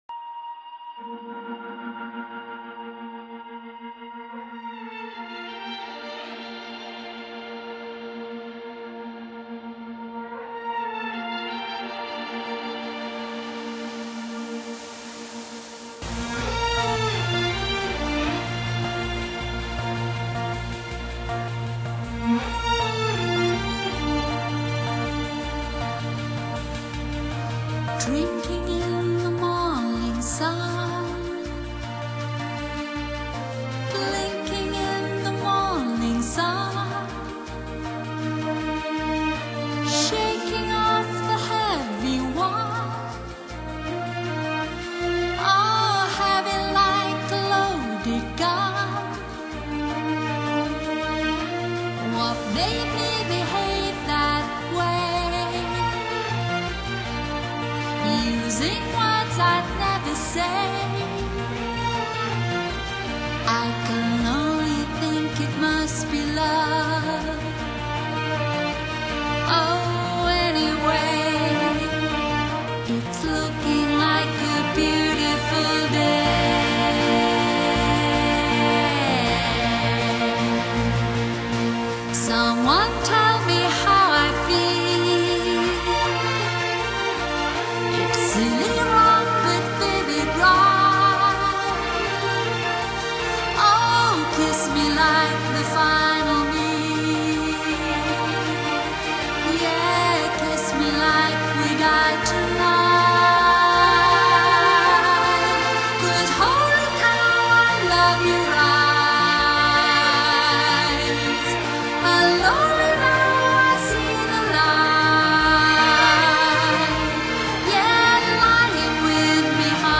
永遠的音樂天使  不朽的美聲天后